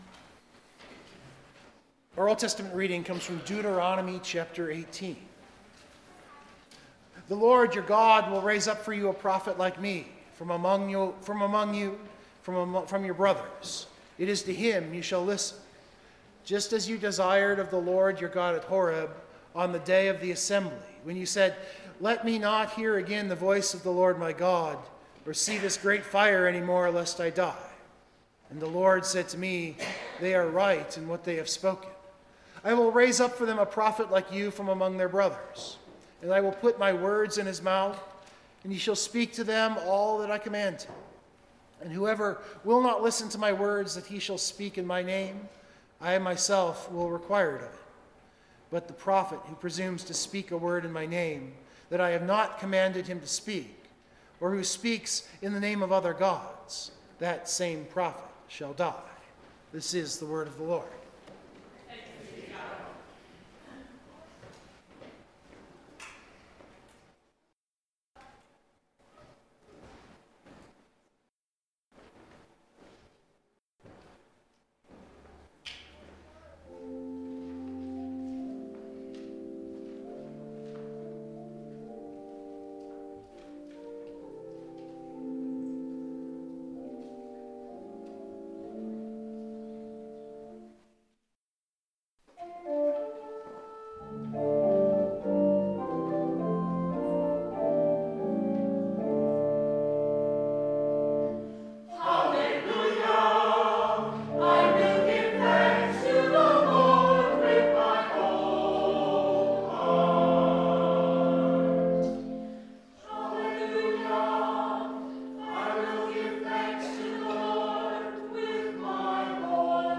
Of course the recording line gets real silent at the end of the gospel reading.
Also the sermon recording might be a little rough as occasionally not even amplifying brings the sound. (I’m thinking I moved a little far away from the mic at those moments and it got lower clipped.)